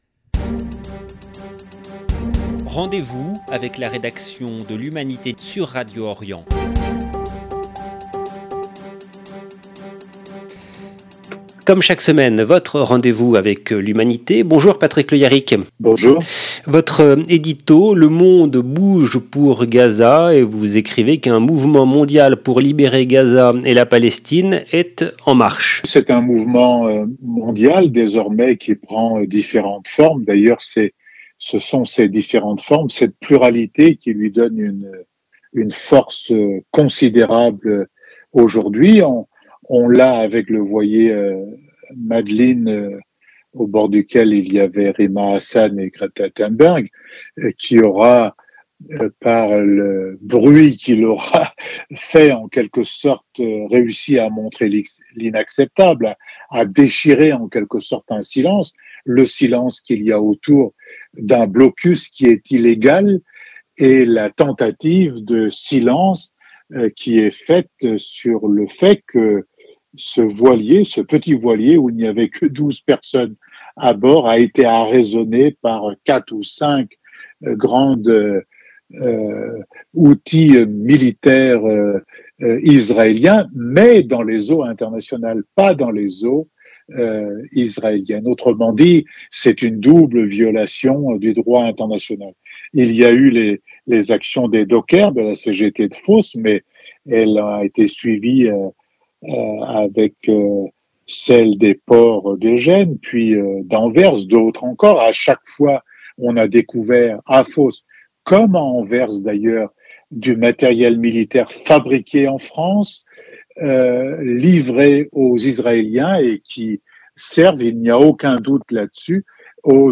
La Chronique de Patrick Le Hyaric du 13 juin 2025
Comme chaque semaine, nous avons rendez-vous avec Patrick Le Hyaric, éditorialiste à L’Humanité.